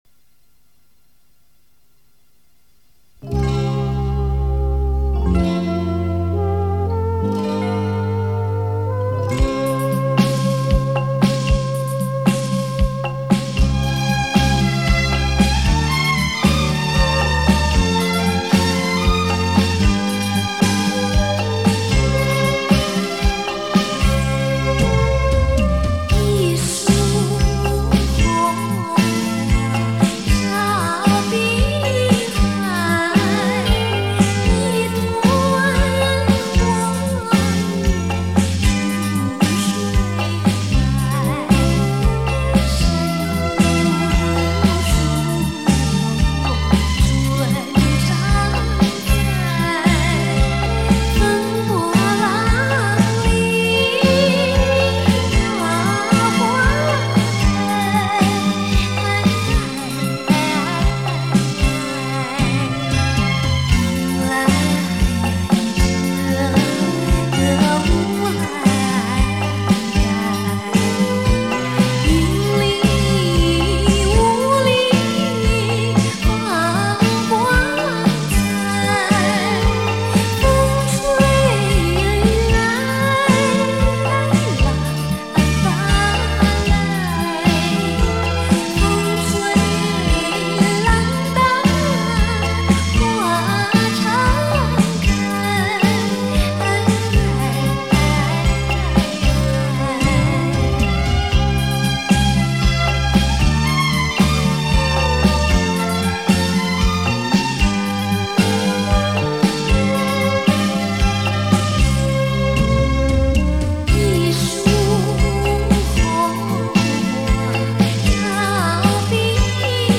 [磁带转WAV]